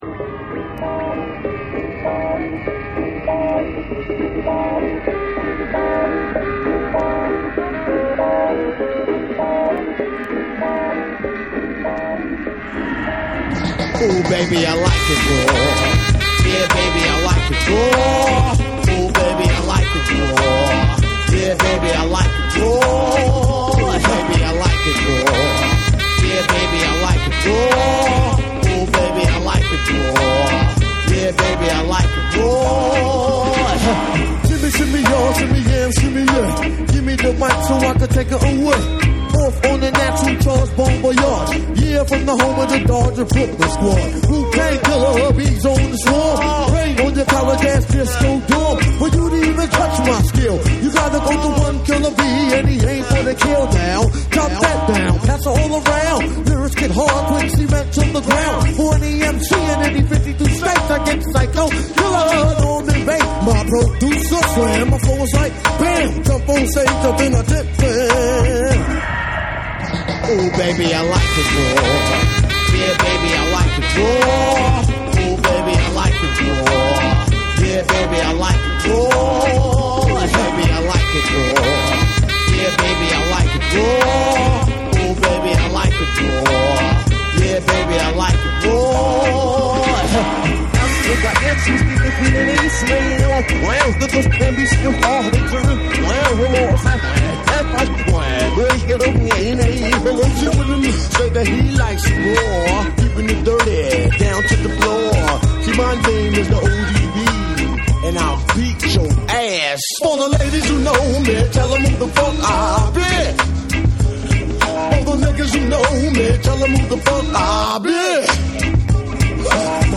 BREAKBEATS / RE-EDIT / MASH UP